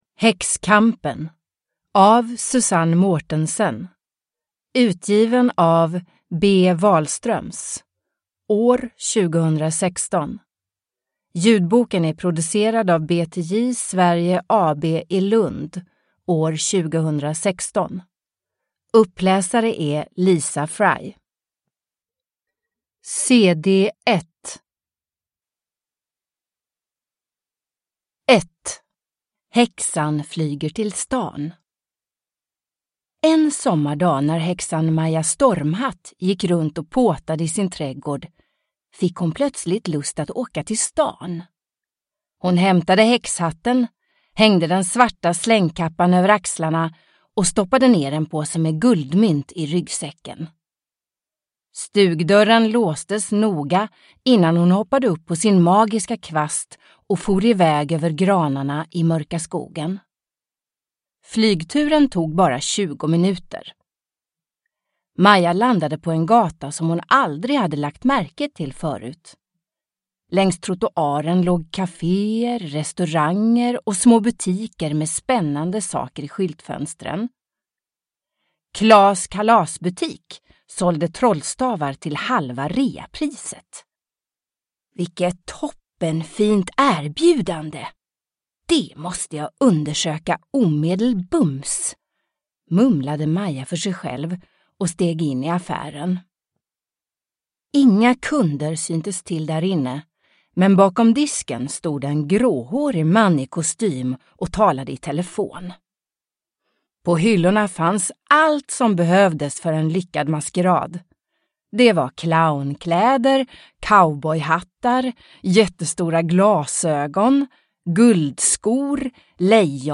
Häxkampen – Ljudbok – Laddas ner